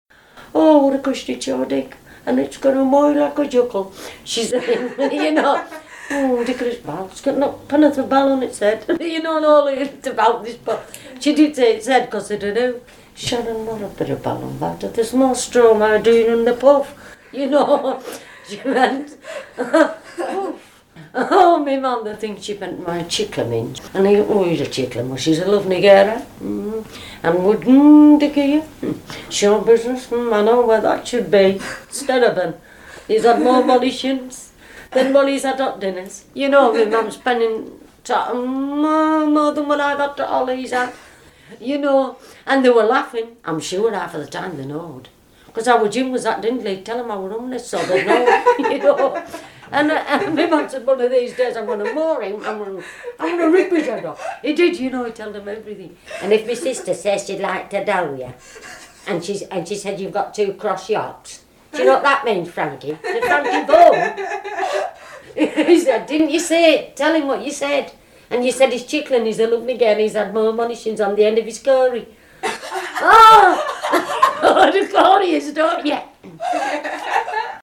Speaker of Angloromani - mixed English-Romani speech of English Gypsies (recorded in the northeast of England, 2007